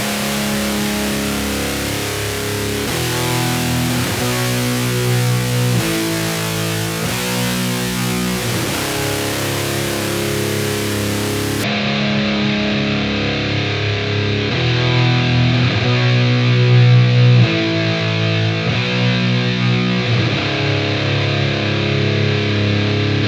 Folk-Prog-Metall - Guitar/JackHammer/Tube AMP StudioV3/M-audio FastTrack USB